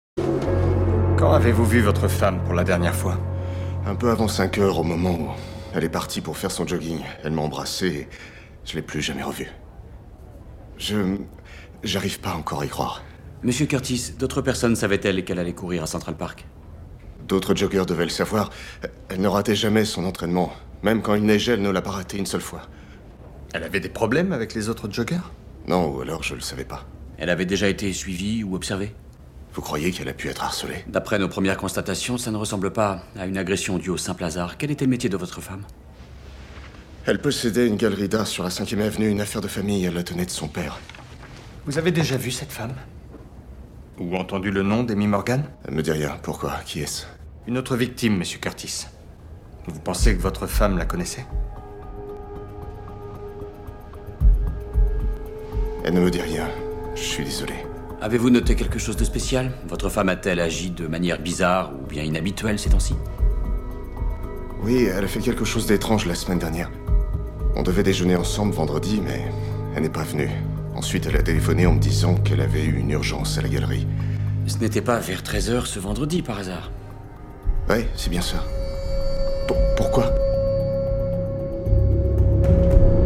Doublage série Castle - Noah Curtis : victime.
Pour ce doublage, j’ai opté pour une voix médium grave, qui correspondait parfaitement à l’état d’esprit du personnage.